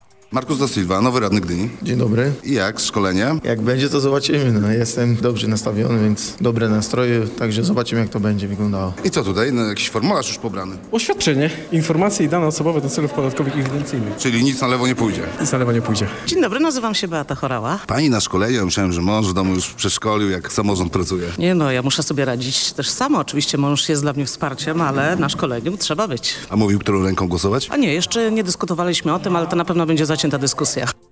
Posłuchaj debiutantów